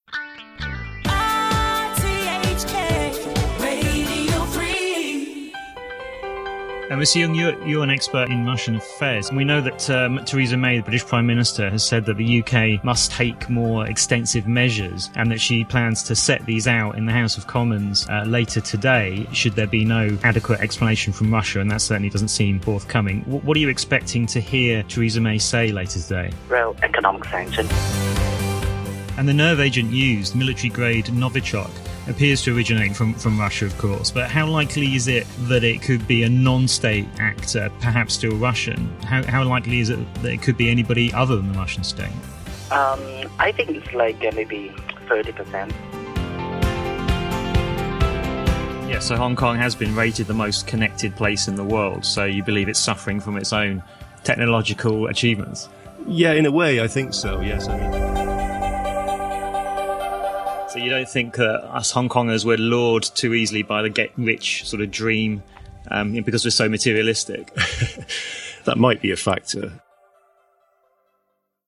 showreel_rthk_backchat_breakfast_current_affairs_1min10.mp3